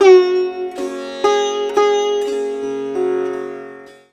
SITAR LINE47.wav